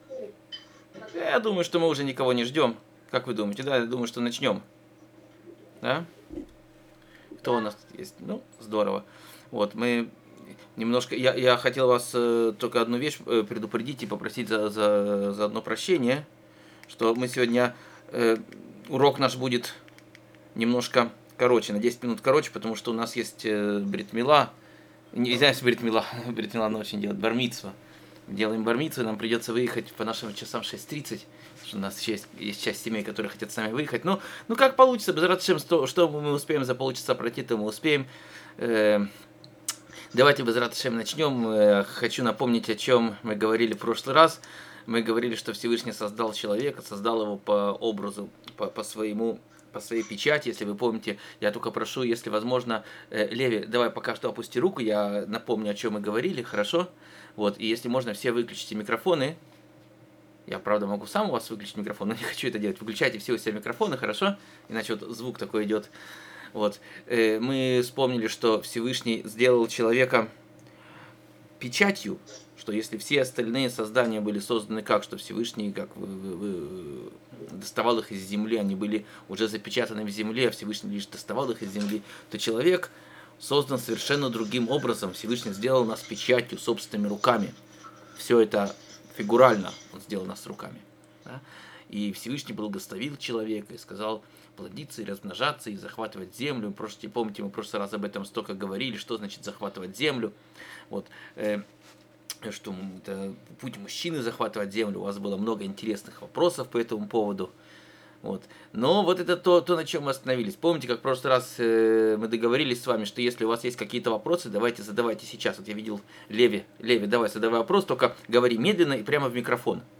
Учит с традиционными напевами, как была передана традиция обучения мальчиков Торе!
Урок 2